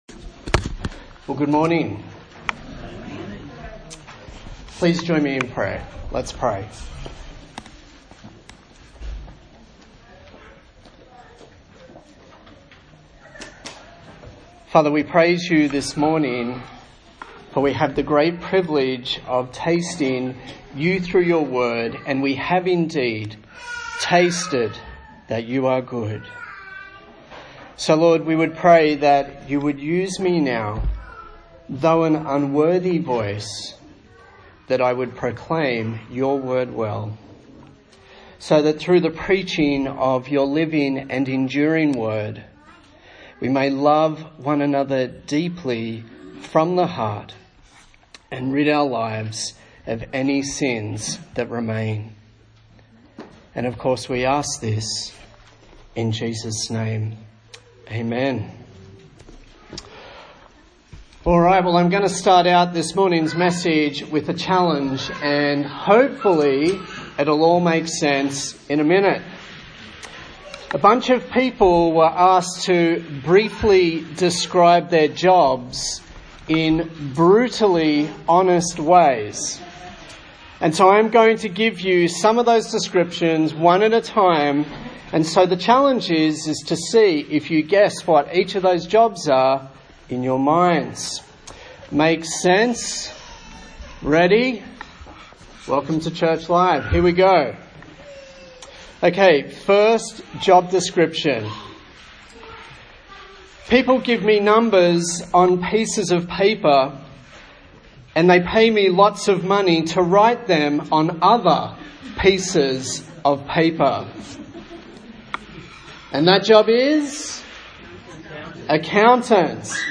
Me and Jesus Preacher
Service Type: Sunday Morning